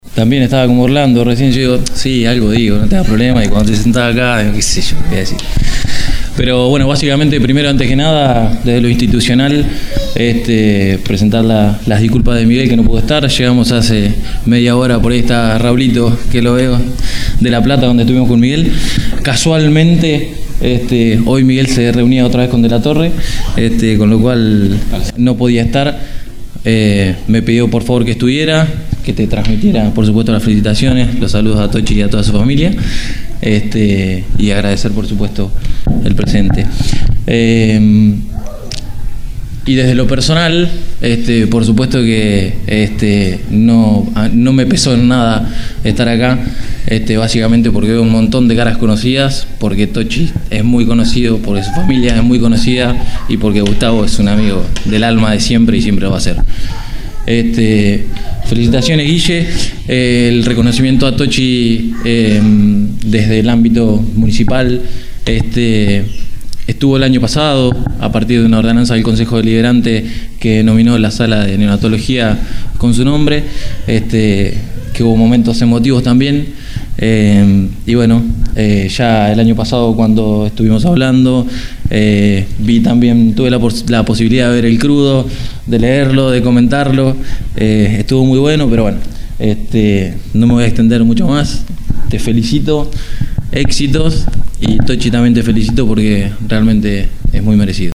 El Secretario de Gobierno de la Municipalidad el Dr. Gustavo Marchabalo estuvo en la presentación del libro «Tochi» y se expresó de esta manera.